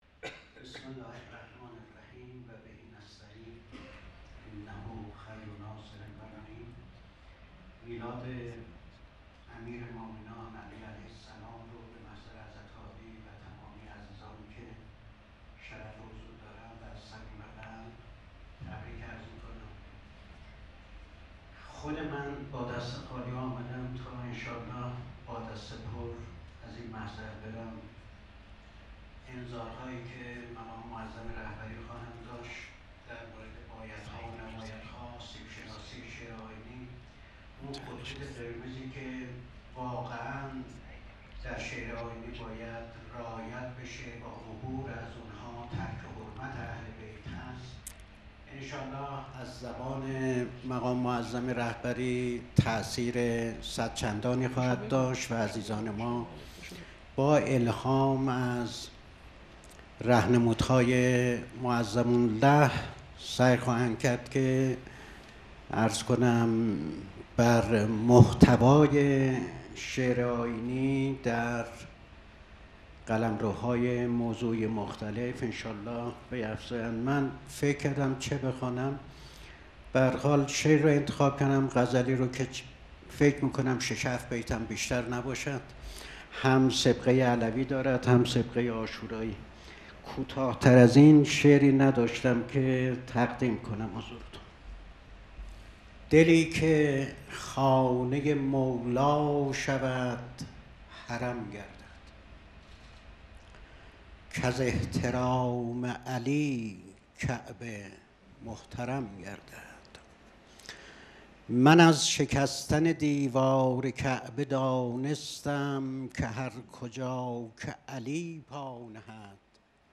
شعر خوانی | دلی که خانه‌ی مولا شود حرم گردد
محفل شاعران آئینی | حسینیه امام خمینی(ره)